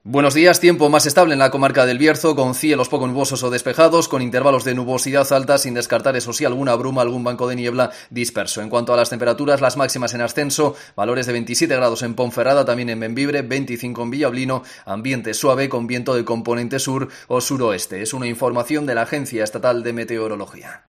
AUDIO: Previsión meteorológica para esta jornada de la mano de la Agencia Estatal de Meteorología (AEMET)